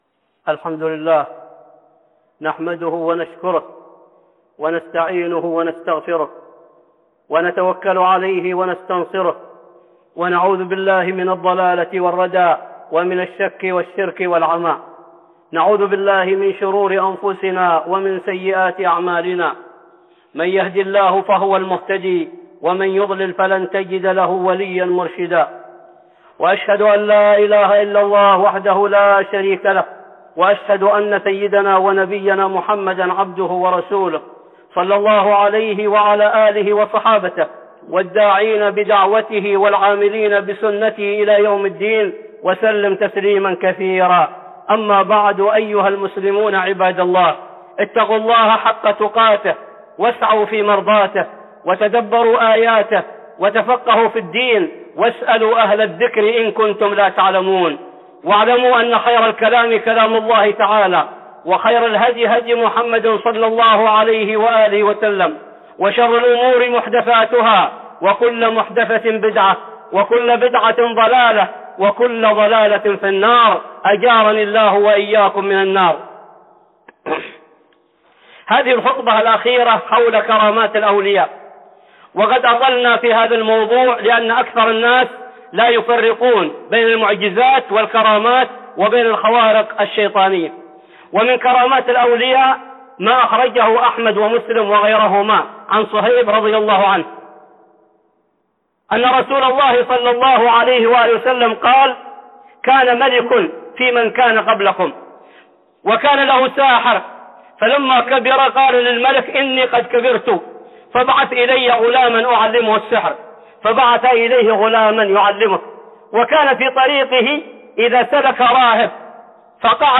(خطبة جمعة) كرامات الأولياء 5